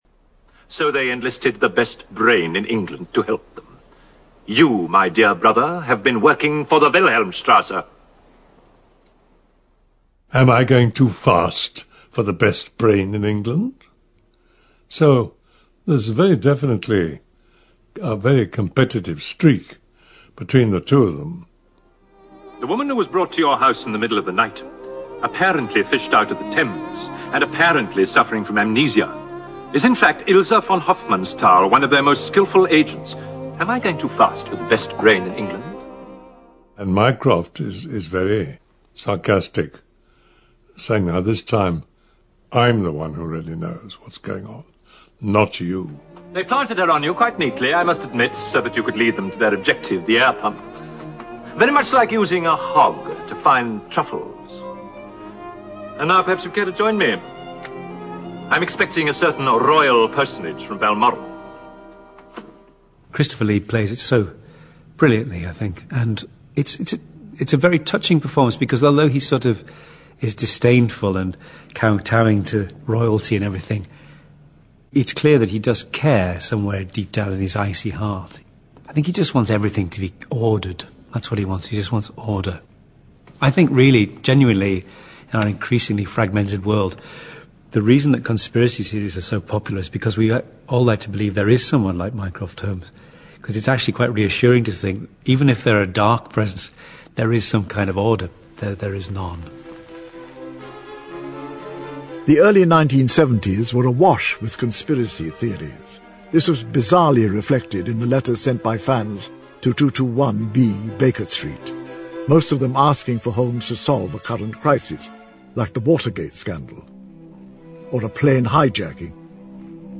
在线英语听力室如何成为福尔摩斯 第21期的听力文件下载, 《如何成为福尔摩斯》栏目收录了福尔摩斯的方法，通过地道纯正的英语发音，英语学习爱好者可以提高英语水平。